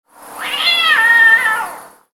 Short High-Pitched Cat Meow Sound Effect
Description: Short high-pitched cat meow sound effect. Aggressive meowing of a domestic cat at night.
Short-high-pitched-cat-meow-sound-effect.mp3